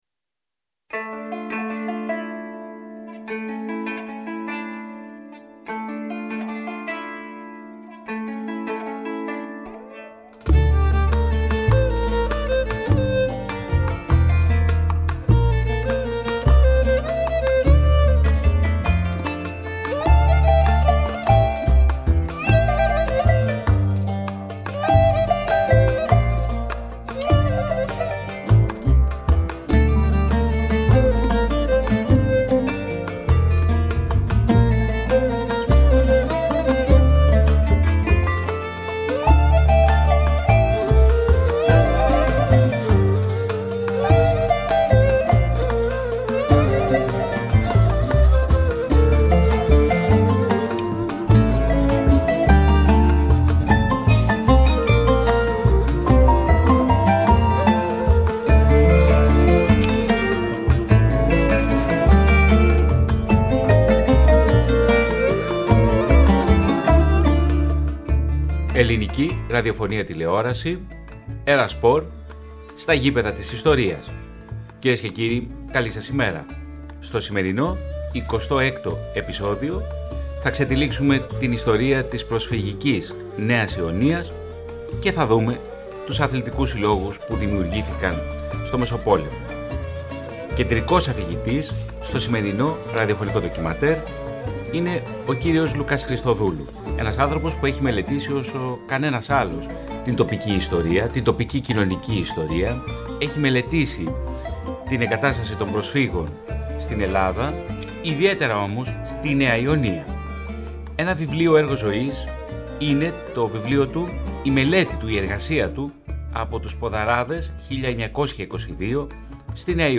ΝΤΟΚΙΜΑΝΤΕΡ